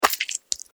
戦闘 （163件）
クリーチャーが食べる音3.mp3